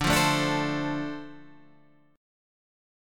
Listen to Dm7 strummed